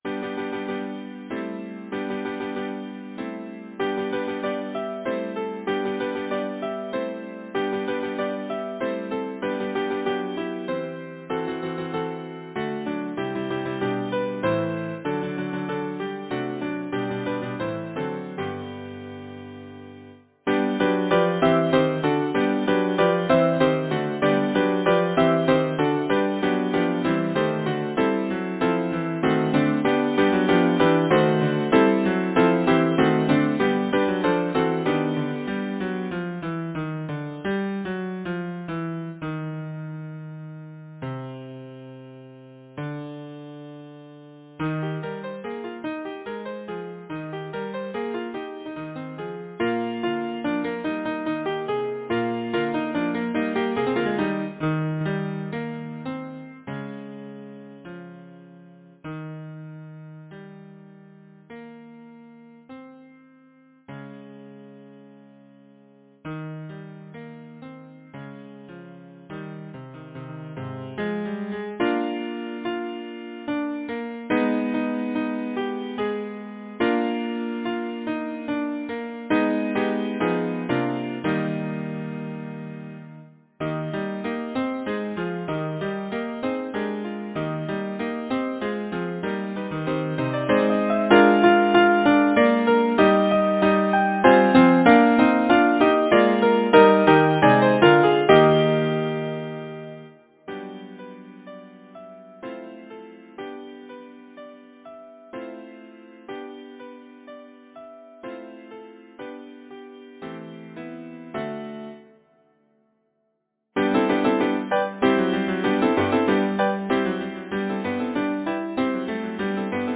Title: Buy broom buzzems Composer: Anonymous (Traditional) Arranger: William Whittaker Lyricist: Number of voices: 4vv Voicing: SATB, divisi Genre: Secular, Partsong, Folksong
Language: English Instruments: A cappella